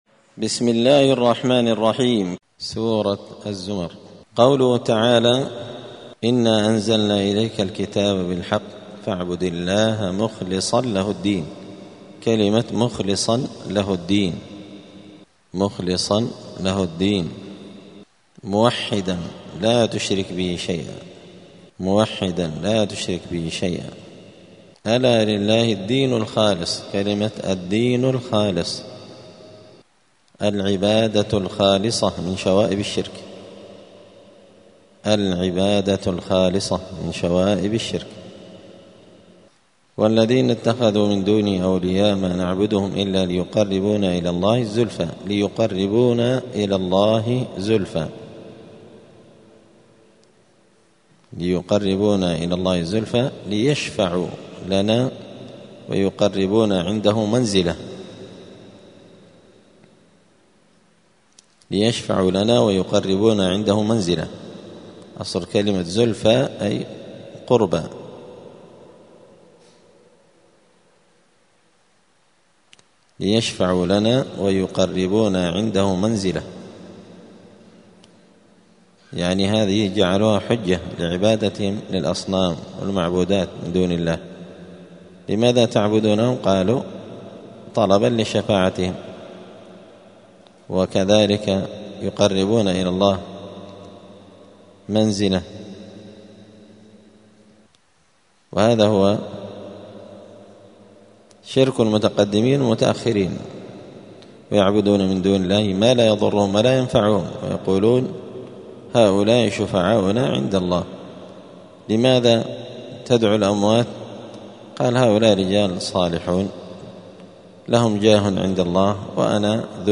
*(جزء الزمر سورة الزمر الدرس 252)*